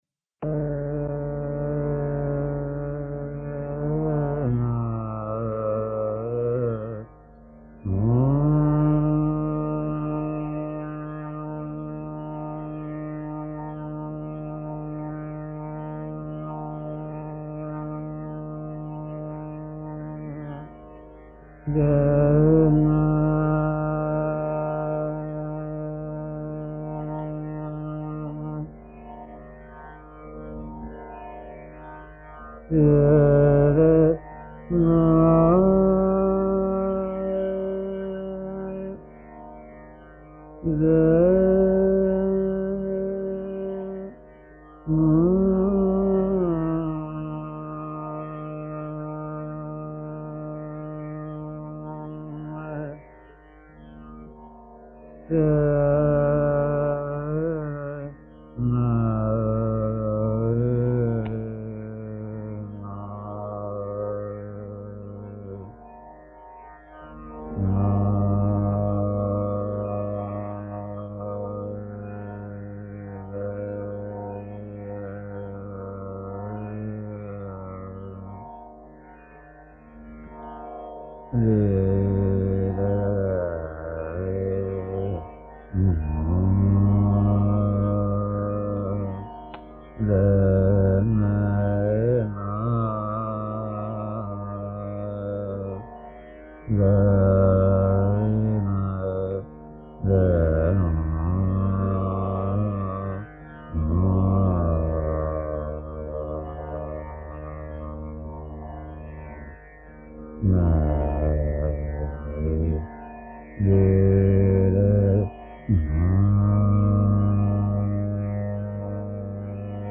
Dhrupad | Nasir Aminuddin Dagar
Blessed with a deep resonant voice, and a slightly restrained devotional style, he was the ideal duet partner for his flamboyant elder brother Moinuddin.
radio broadcast